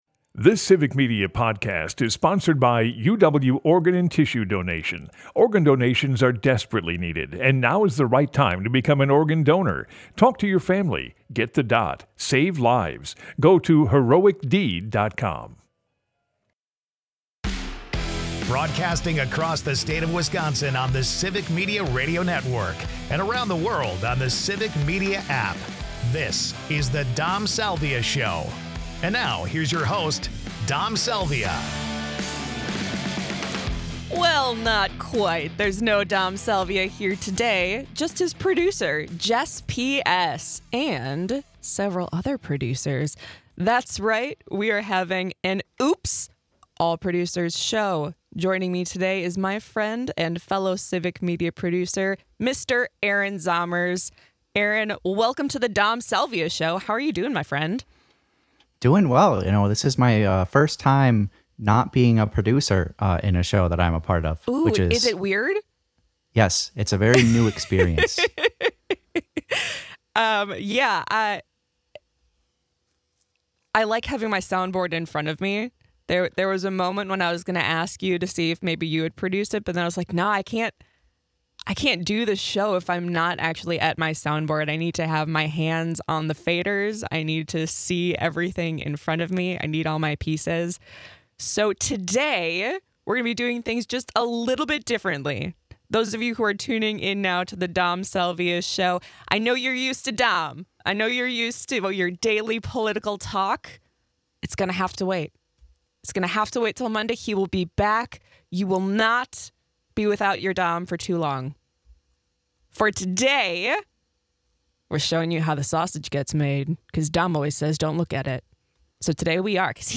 So for this episode, each bumper is one of my top picks, listed in order they played on the show: